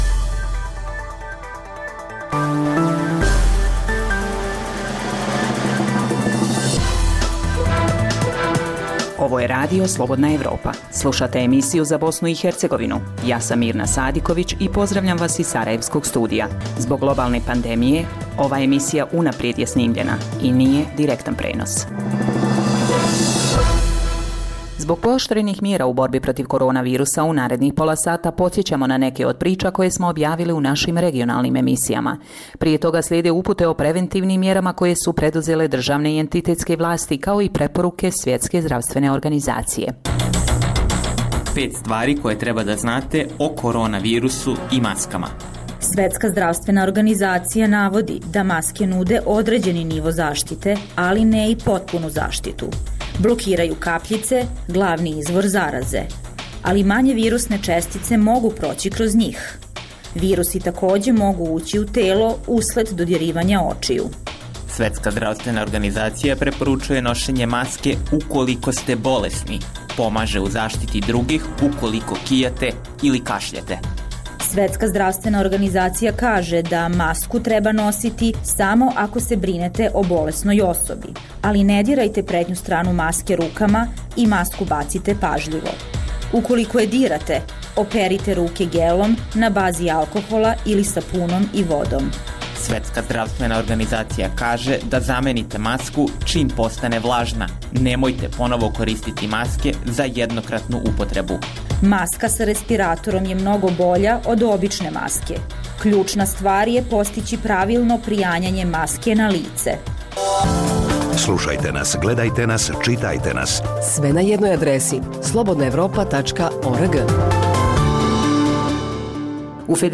Zbog pooštrenih mjera kretanja u cilju sprječavanja zaraze korona virusom, ovaj program je unaprijed snimljen. Poslušajte neke od priča koje smo objavili u regionalnim emisijama